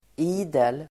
Uttal: ['i:del]